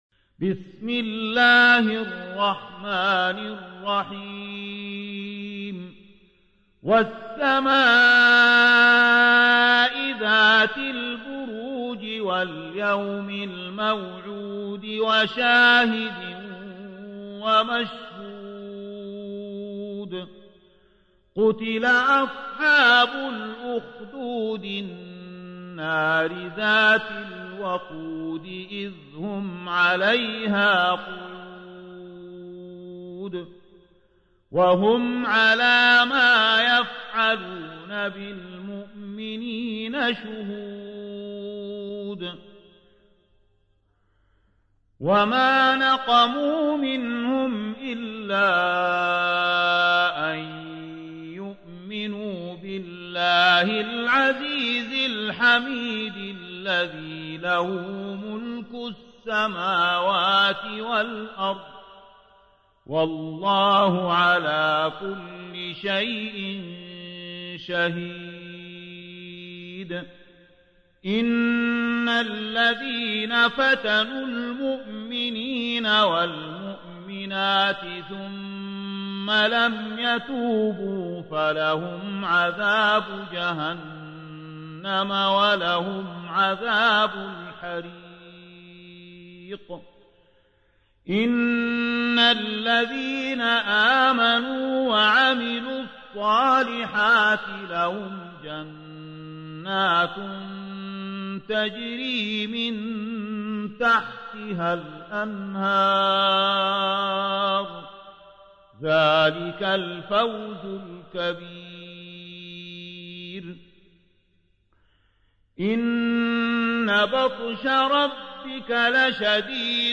تحميل : 85. سورة البروج / القارئ زكي داغستاني / القرآن الكريم / موقع يا حسين